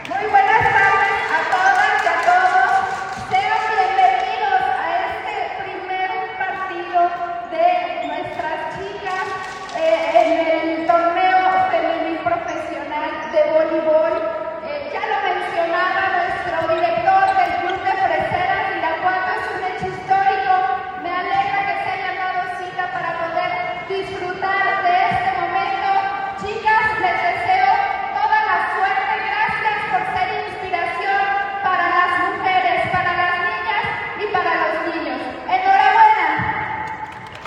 AudioBoletines
Valeria Alfaro García, presidenta del Sistema DIF Municipal